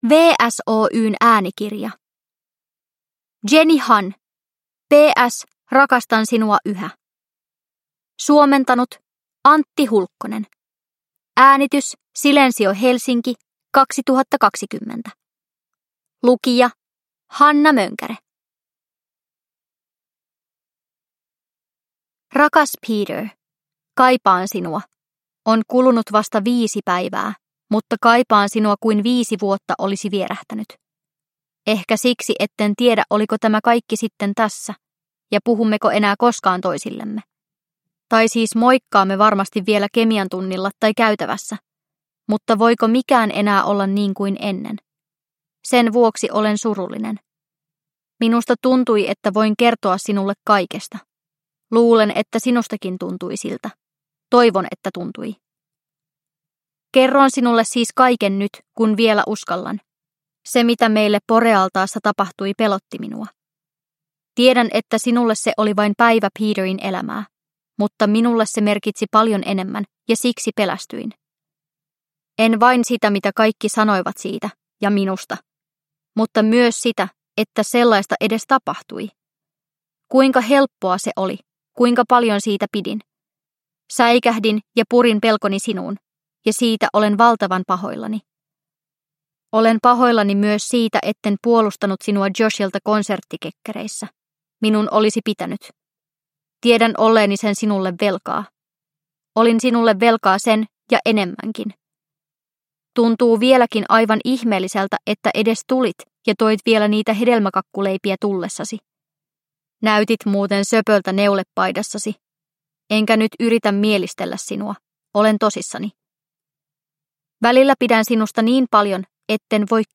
P.S. Rakastan sinua yhä – Ljudbok – Laddas ner